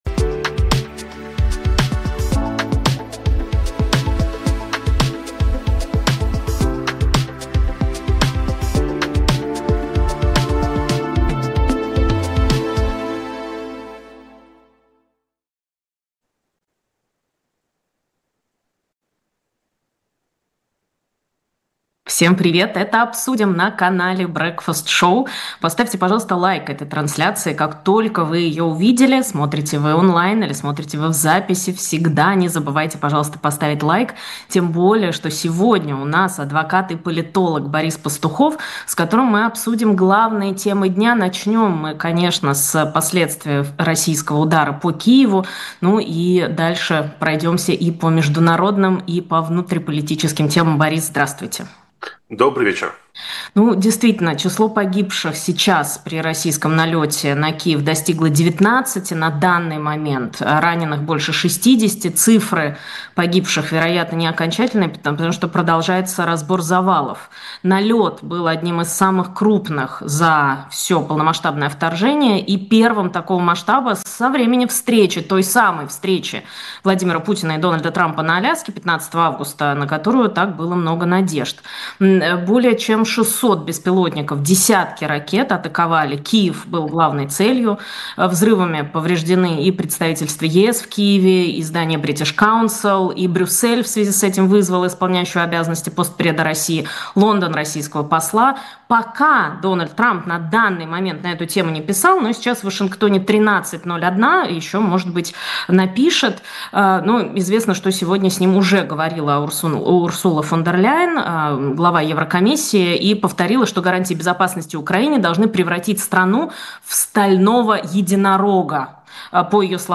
политолог